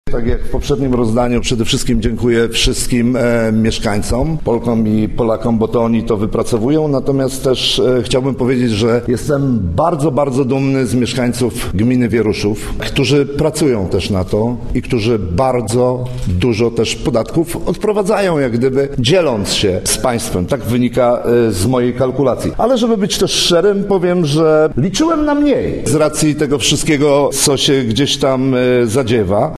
– komentował otrzymanie dotacji burmistrz Wieruszowa Rafał Przybył